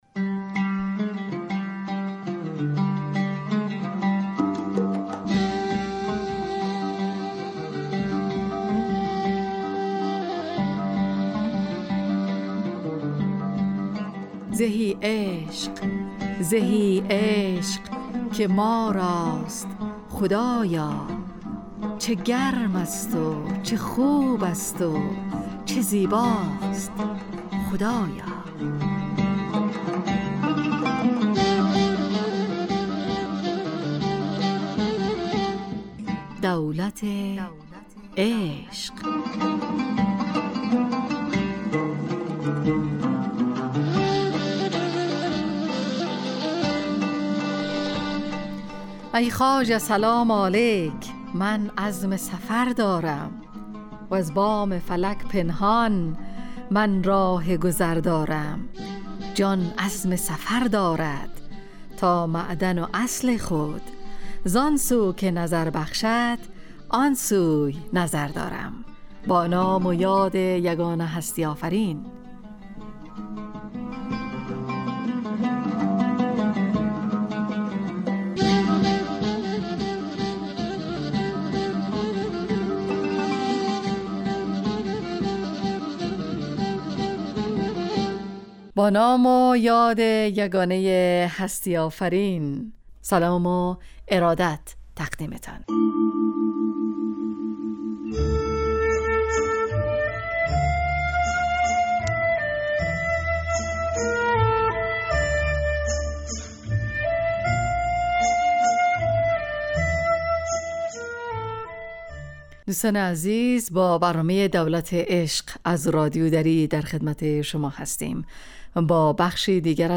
دراین برنامه به معرفی مولوی جلال الدین محمد بلخی معروف به مولانا می پردازیم و در قسمتهای مختلف داستانهایی از مولوی را بیان می کنیم. پخش بخش هایی نظیر ترانه هایی از مولانا و همچنین مولوی خوانی در برنامه گنجانده شده است.